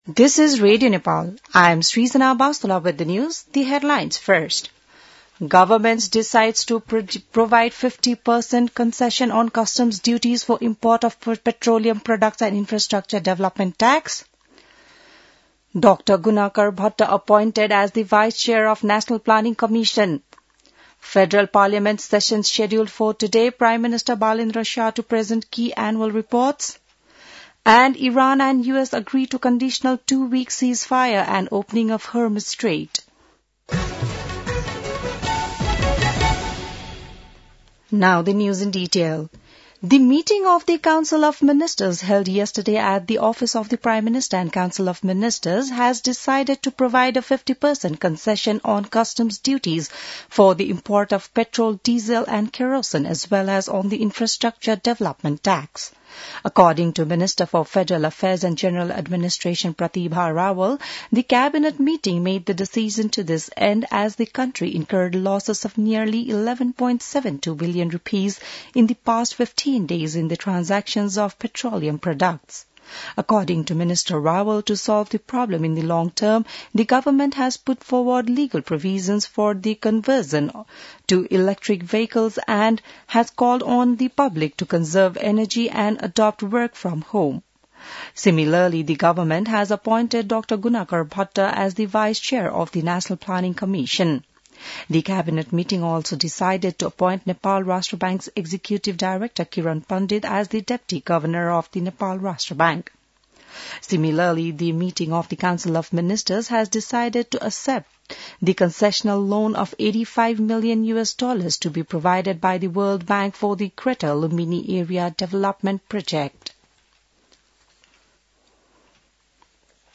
बिहान ८ बजेको अङ्ग्रेजी समाचार : २५ चैत , २०८२